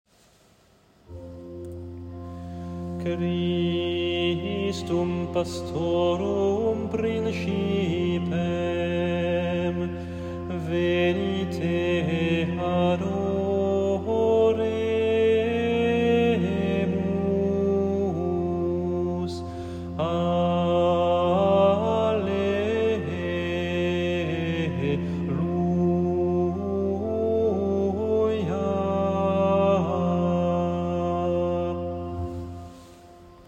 Antienne invitatoire (temps pascal) : Christum, pastorum [partition LT]